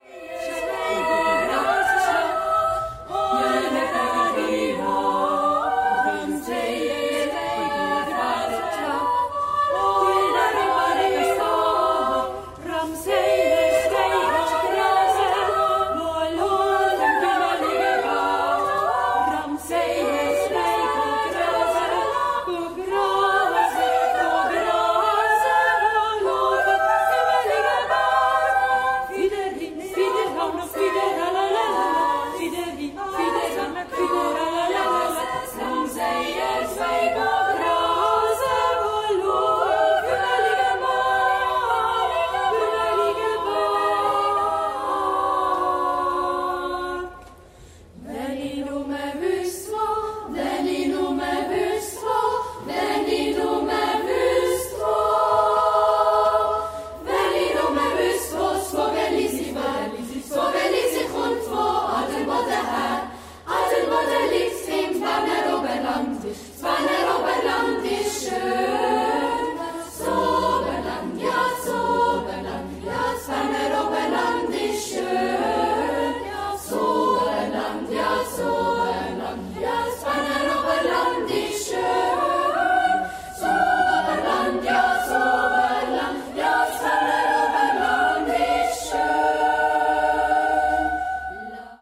SSAA a cappella.